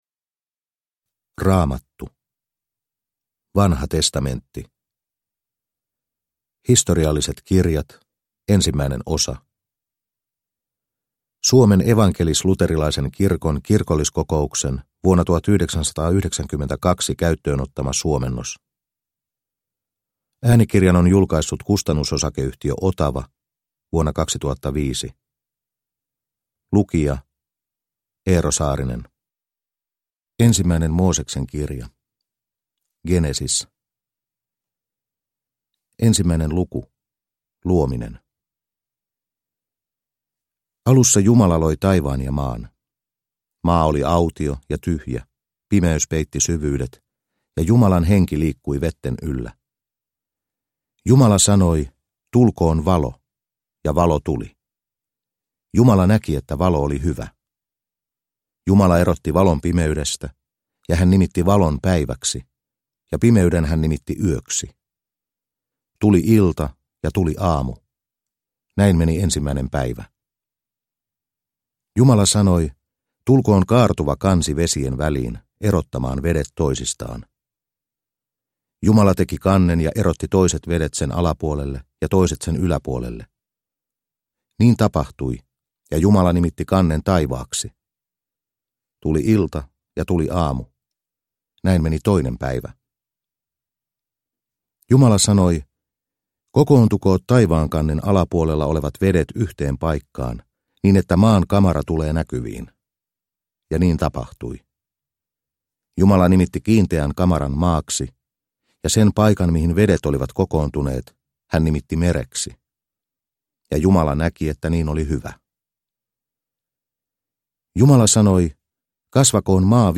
Vanha testamentti osa 1 (ljudbok) av -